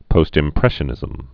(pōstĭm-prĕshə-nĭzəm)